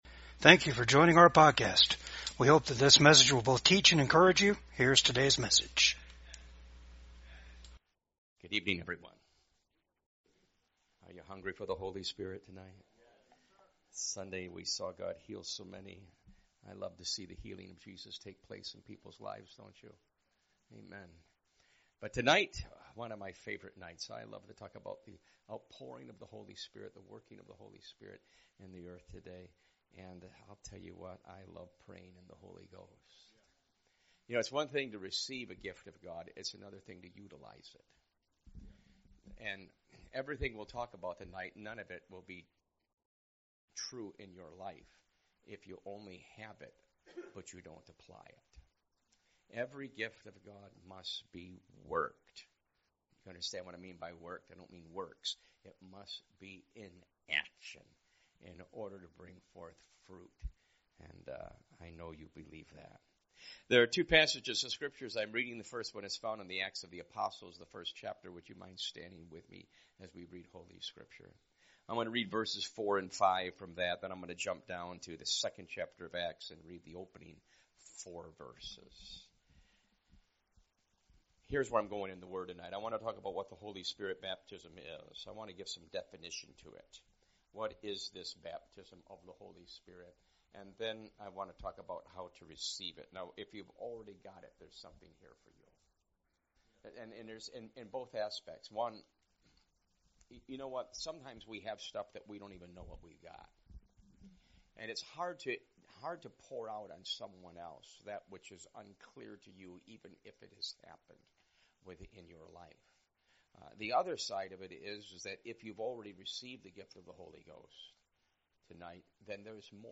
Service Type: REFRESH SERVICE